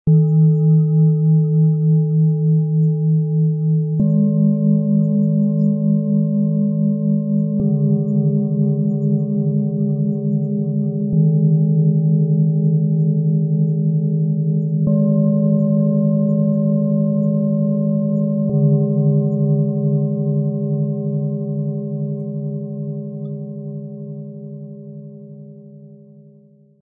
Klangschalen-Set Ø 14,9-16,3 cm (1,42 kg) - Klarheit, Aufbruch -Kraft im Sound-Spirit Shop | Seit 1993
Planetenschalen-Set mit tiefer Klangfülle.
Das Set klingt tief, tragend und wandelbar.
Tiefster Ton: Wasserstoffgamma - Klarheit & geistige Weite
Dünnwandig gefertigt, reich an Obertönen.
Höchster Ton: Mars - Energie & Lebenskraft
Besonders hörbar: die tiefe Resonanz und die klangliche Vielfalt durch dünnwandige Fertigung.
MaterialBronze